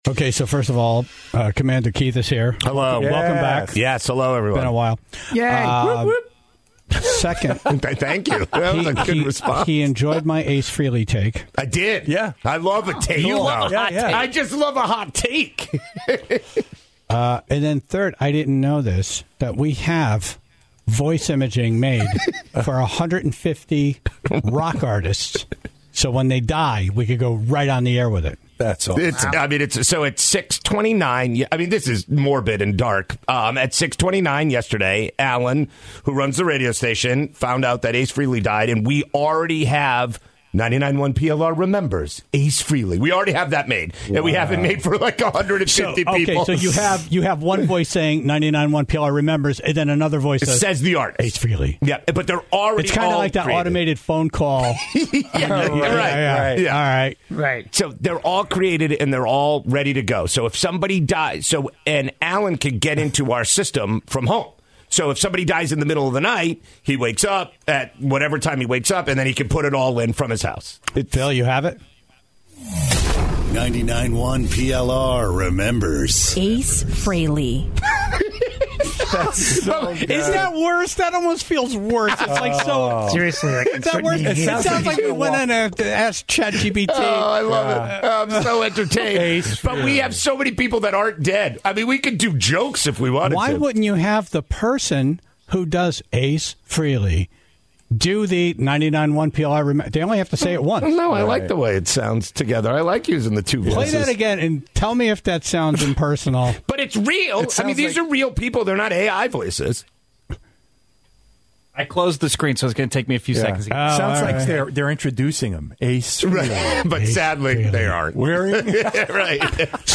welcomed him back in studio for a Top 5 list chock-full of misinformation and sure-fire ways to get people sick.